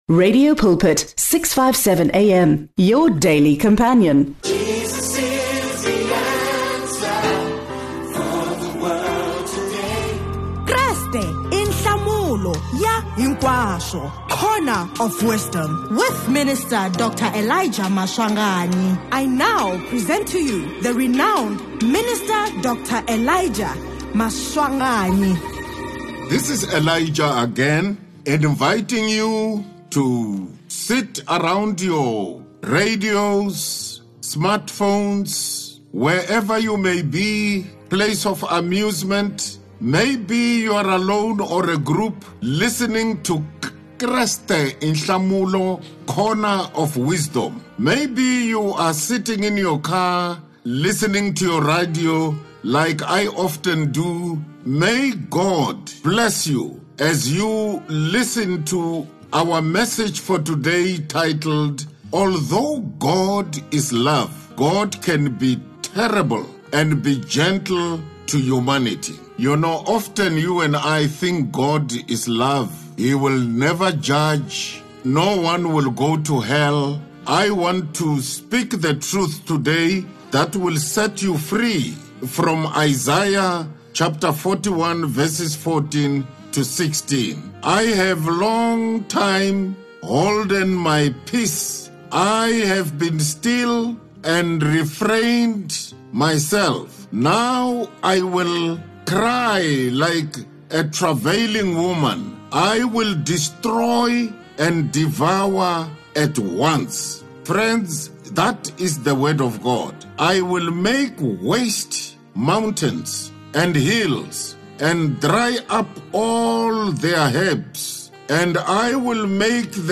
Listeners can expect a fresh Word from God early in the morning every weekday. Pastors from different denominations join us to teach the Word of God.